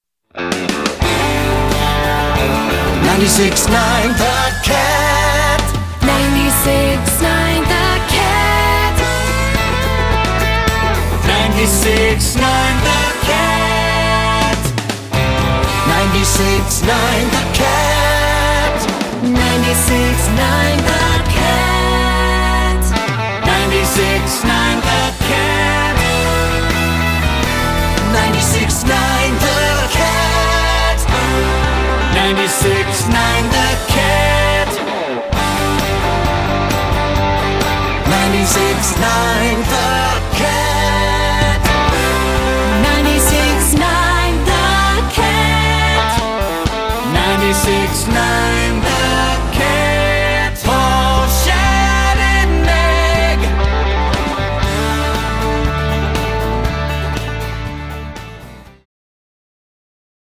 mainstream Nashville country